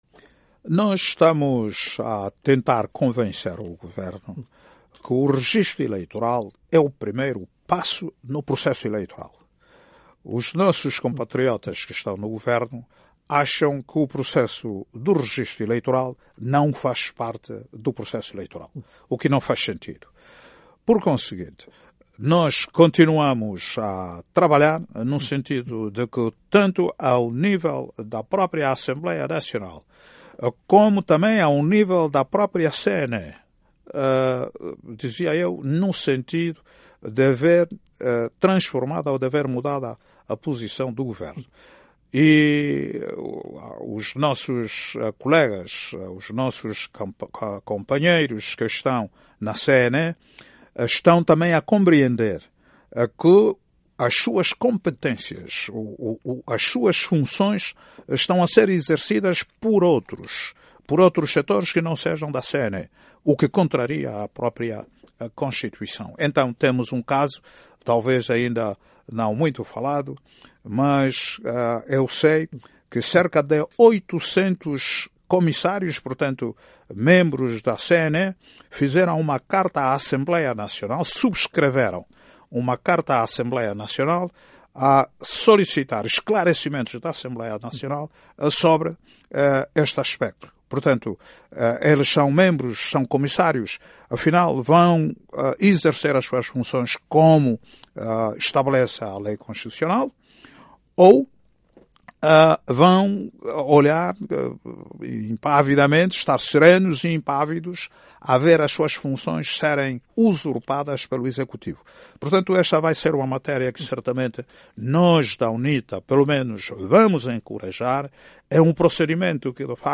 Numa entrevista à VOA, Samakuva manifestou preocupação com o registo eleitoral que vai iniciar este mês, em Angola.